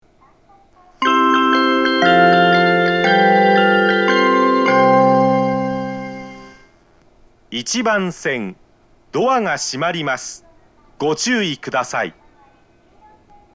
2010年12月に放送更新し、音質が向上しています。
発車メロディー
一度扱えばフルコーラス鳴ります。
スピーカーが収録しにくい場所に設置されています。